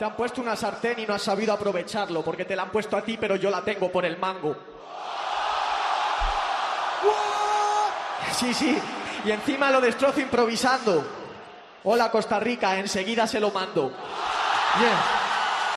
Y no solo esto, BNET es capaz de rimar al momento con una sartén y un teléfono de esta forma...
Rima de BNET durante la semifinal